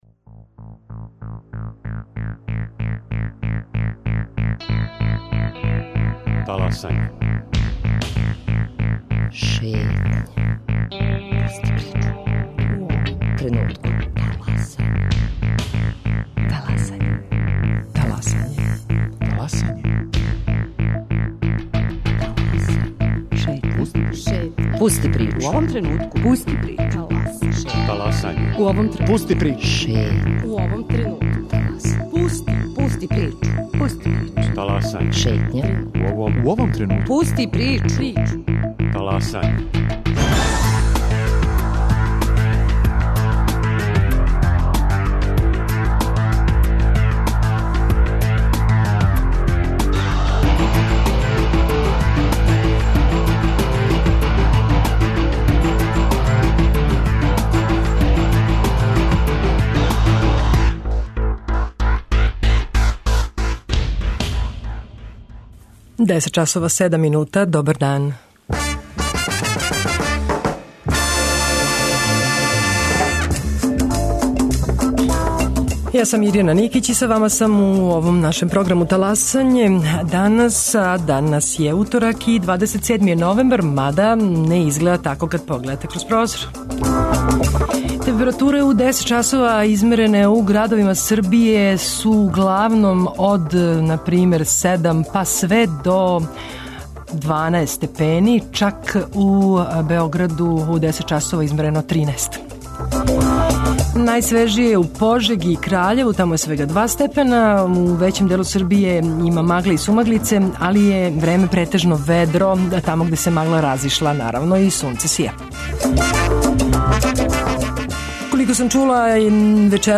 За Шетњу о кршењу права радника, дискриминацији и могућностима заштите од мобинга, говоре организатори кампање и представници удружења "Стоп мобинг" из Новог Сада.